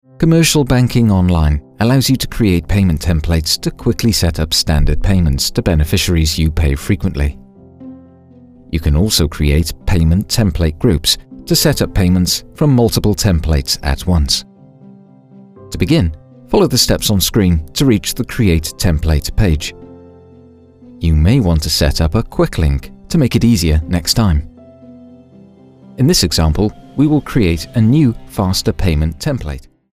OFF-Voice Englisch (UK)